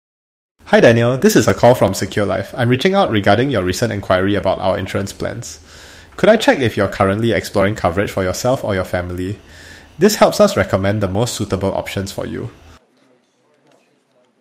Custom-built AI voice agents trained on real APAC languages and accents to deliver natural, clear, and human-like conversations
Singapore English Voice Agent
ai-lead-qualification-call-singapore.mp3